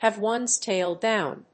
アクセントhàve one's táil dówn [úp]